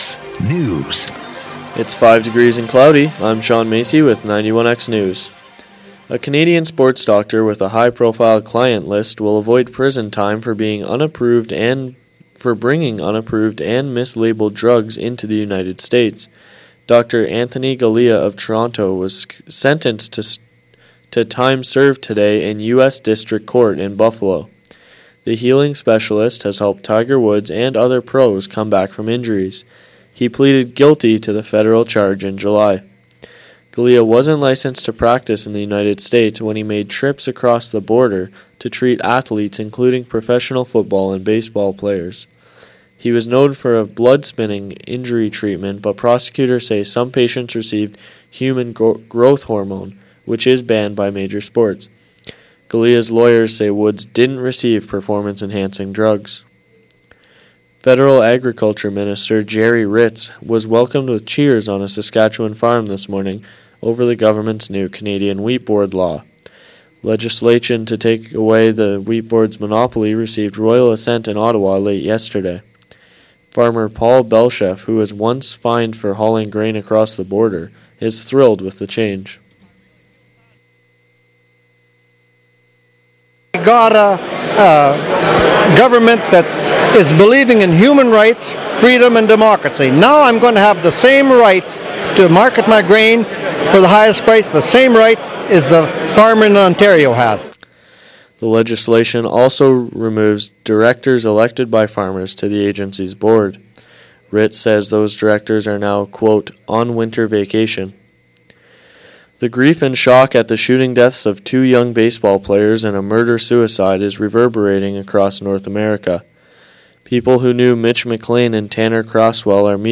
A Canadian sports doctor with a high-profile client list will avoid prison time for bringing unapproved and mislabelled drugs into the United States.Federal Agriculture Minister Gerry Ritz was welcomed with cheers on a Saskatchewan farm this morning over the government’s new Canadian Wheat Board law and the grief and shock over the shooting deaths of two young baseball players in a murder-suicide is reverberating across North America. For these stories, sports and more listen to the 4 p.m. newscast.